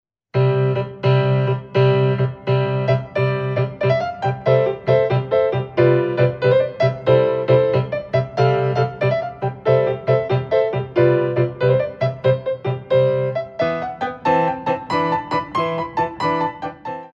Ballet class music for first years of ballet
8x8 - 6/8